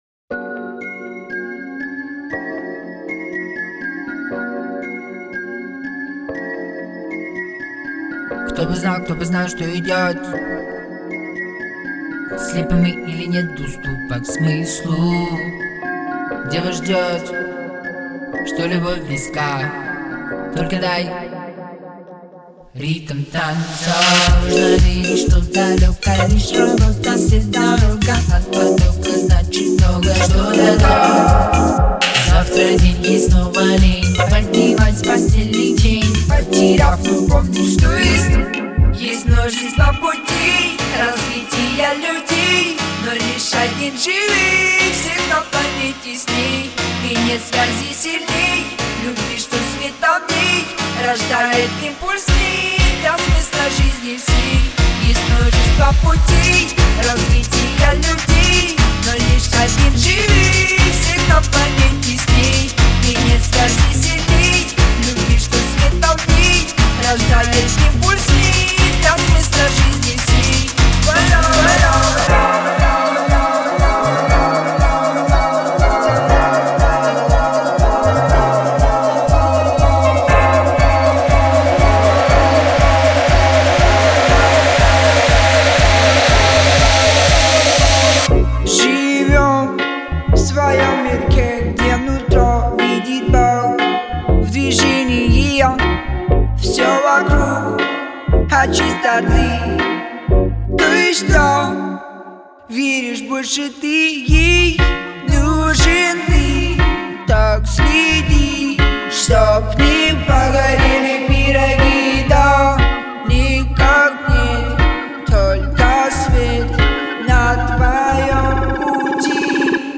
передает эмоции через свою вокальную подачу